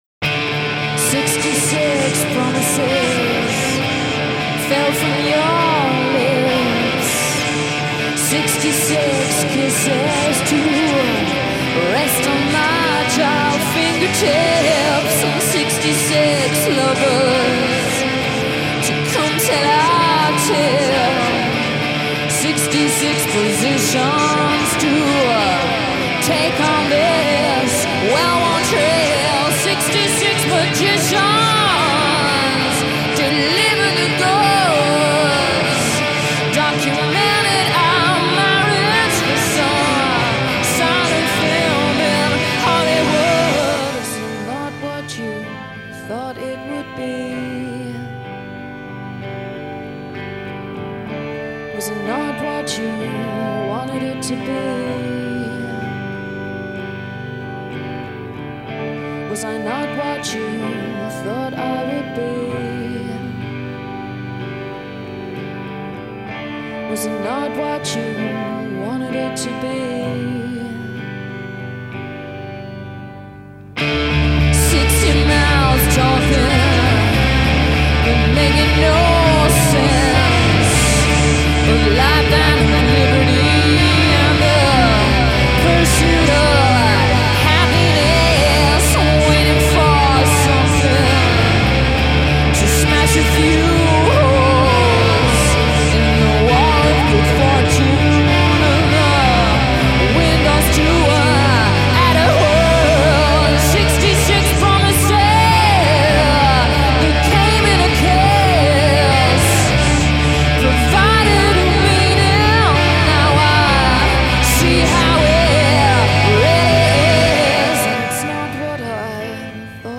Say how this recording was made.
live radio session versions